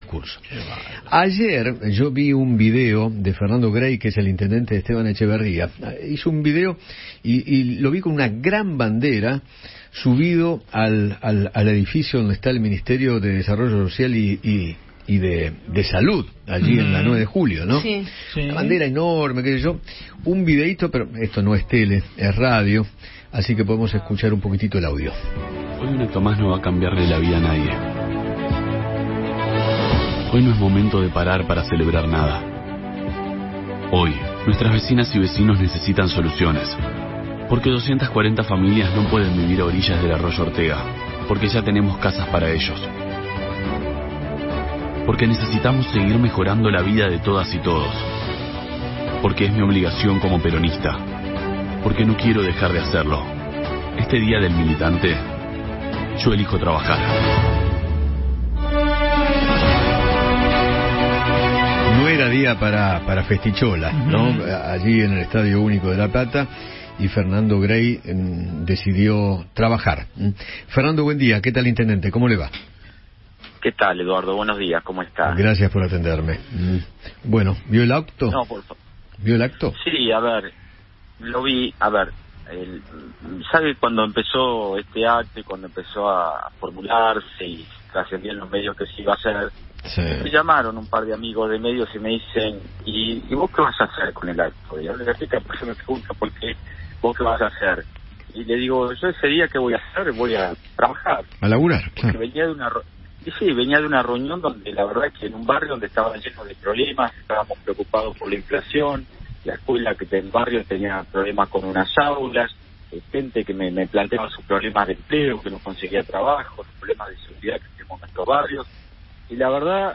Fernando Gray, intendente de Esteban Echeverría, dialogó con Eduardo Feinmann sobre el video que compartió en sus redes sociales después del discurso de Cristina Kirchner en La Plata.